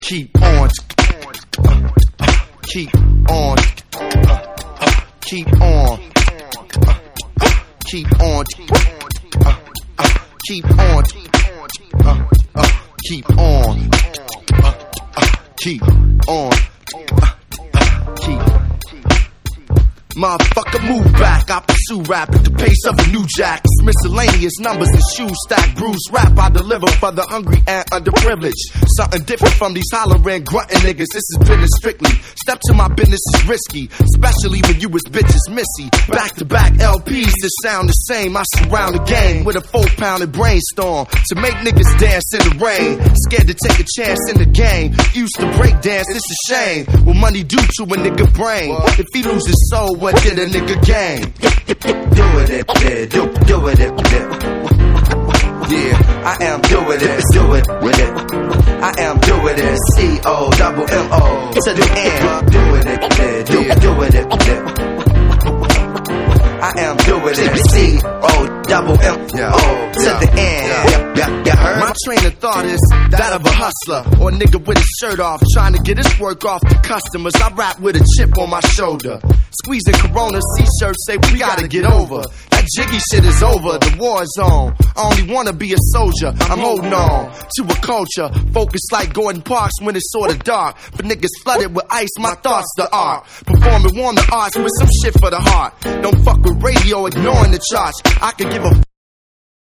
アーバン・メロウなサンプルとファンキーなビート
BREAKBEATS / HIP HOP